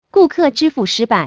PayFail.wav